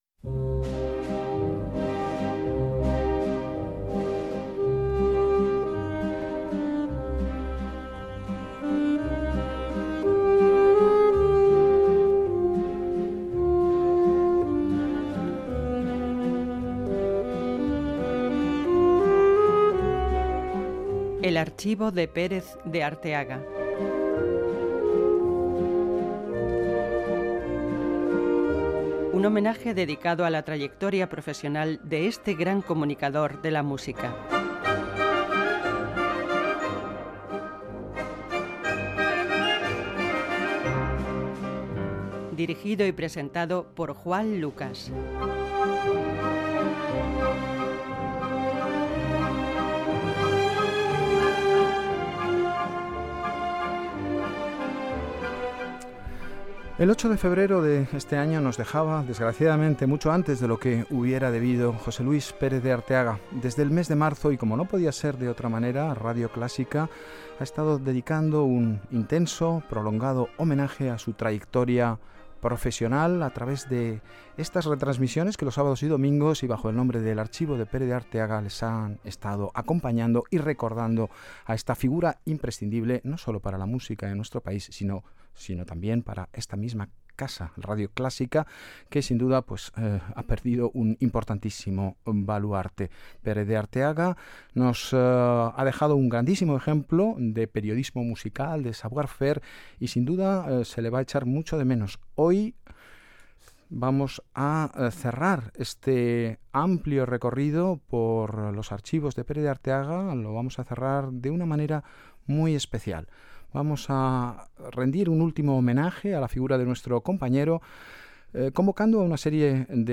Careta del programa, presentació, tema musical i conversa
Gènere radiofònic Musical